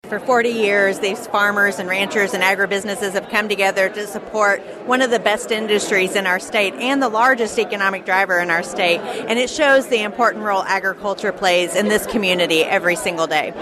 Mull Farms outside Malta Bend hosted the event, which saw over 120 area farmers and ag leaders enjoy lunch and several speeches.
After the event, she reflected on the role Ag Day has played in celebrating the area’s farmers for 40 years.